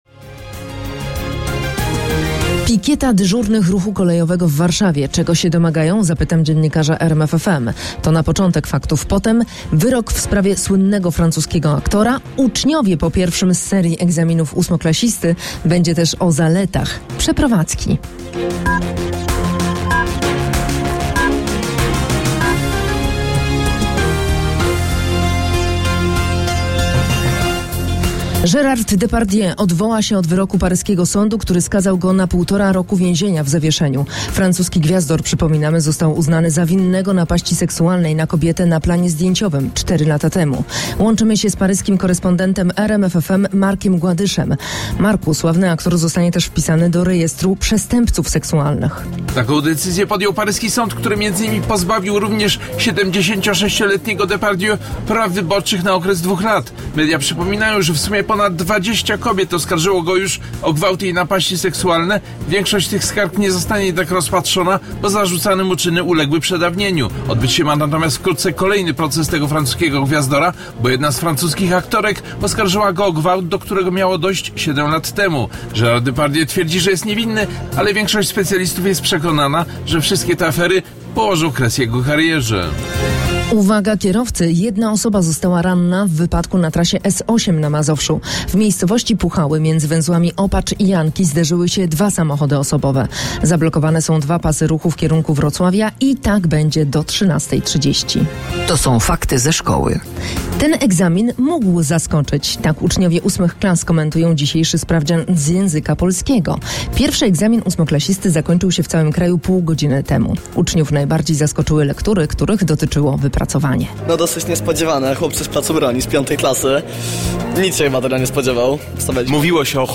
Inga prenumerationer eller installationer behövs. 09:00 Fakty. 08:30 Fakty i Fakty sportowe.
Najświeższe wiadomości z kraju i świata przygotowywane przez dziennikarzy i korespondentów RMF FM. Polityka, społeczeństwo, sport, kultura, ekonomia i nauka.